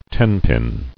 [ten·pin]